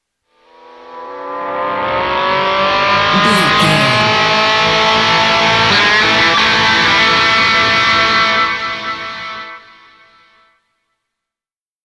Epic / Action
Ionian/Major
electric guitar
Slide Guitar